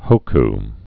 (hōk)